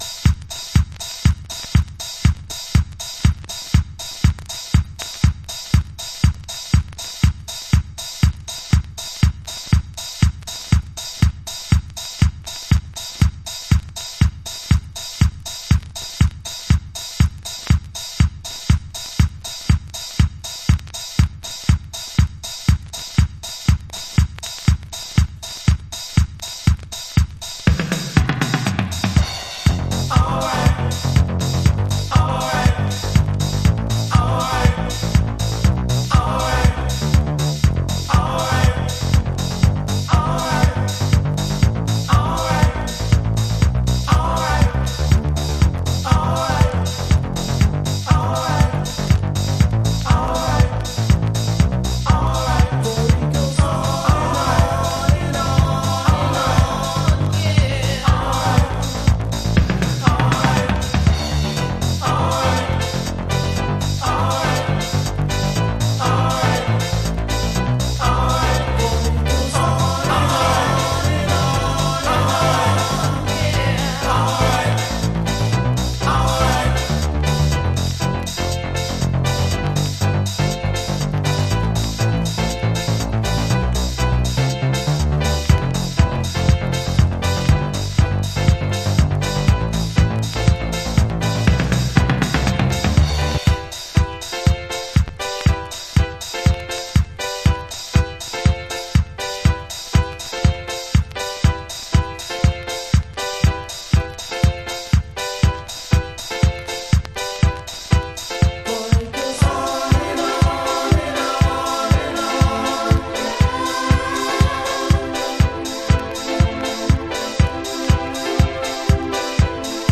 CHI-HOUSE古典
Dub
Chicago Oldschool / CDH